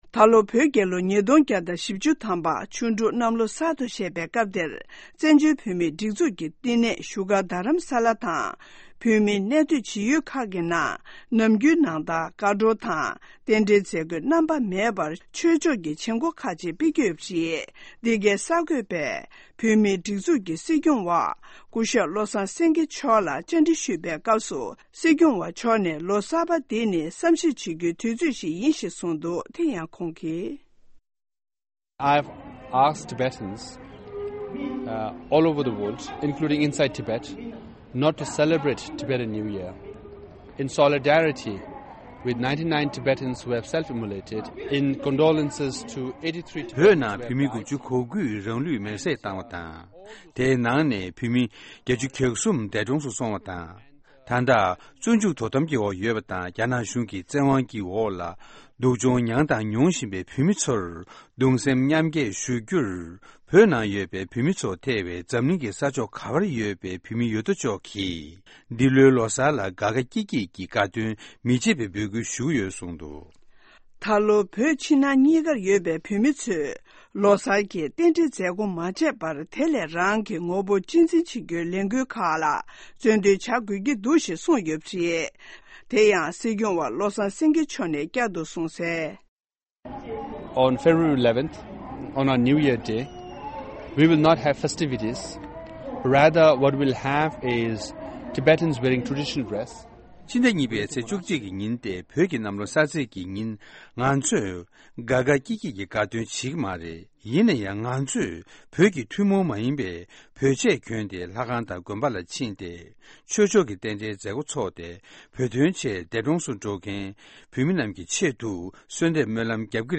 སྲིད་སྐྱོང་གི་ལོ་གསར་གསུང་བཤད།